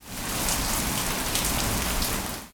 rain_0.ogg